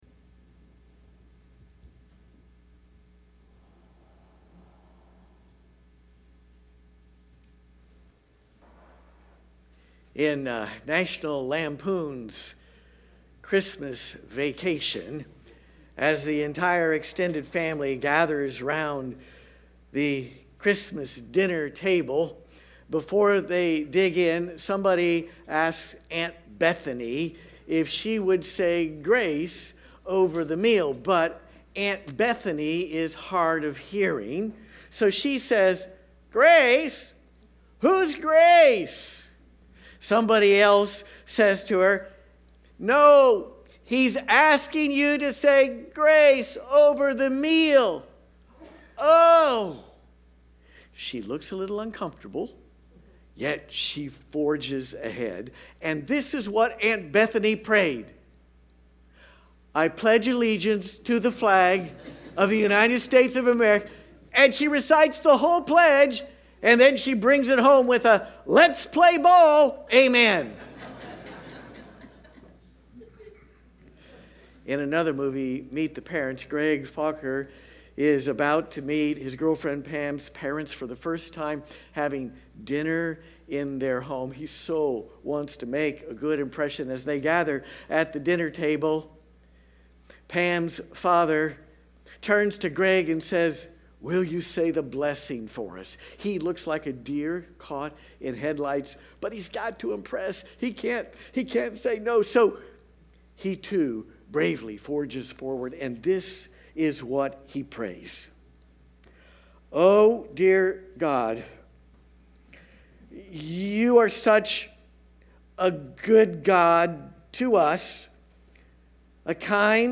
LIVE Stream Replay